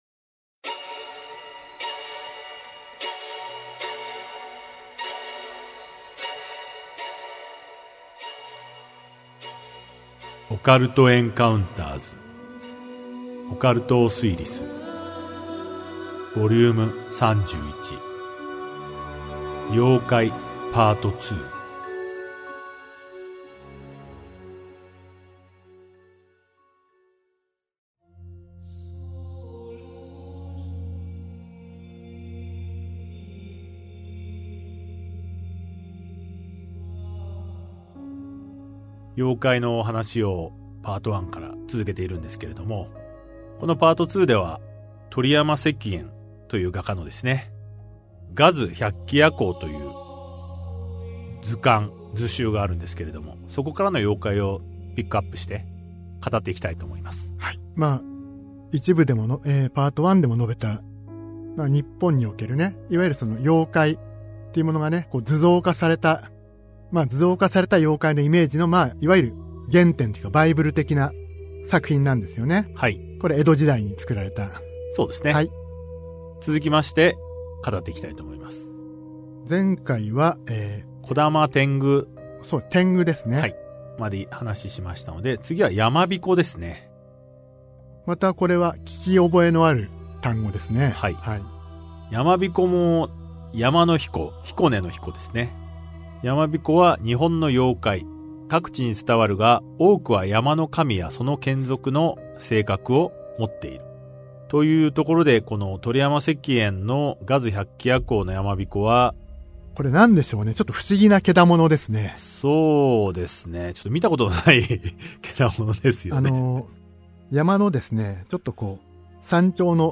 オカルト・エンカウンターズの二人が伝承と文献を紐解き、闇に蠢く住人たちの正体を推理する──。